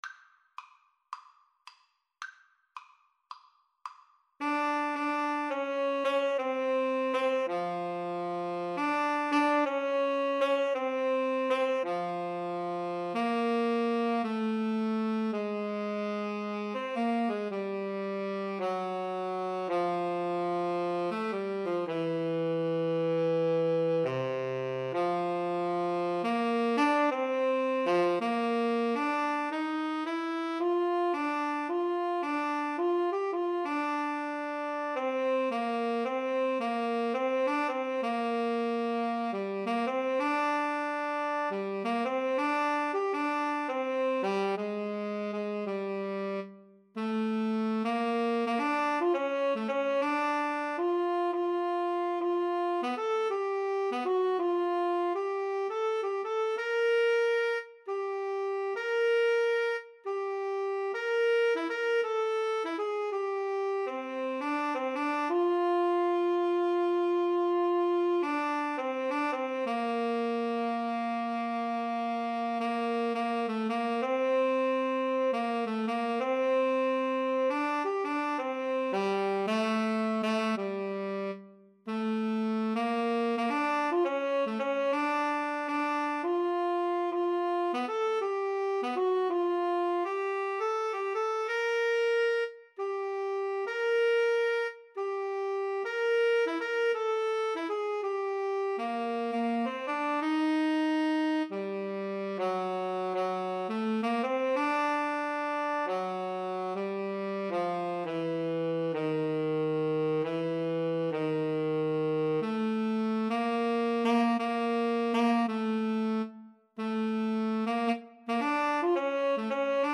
~ = 110 Moderate swing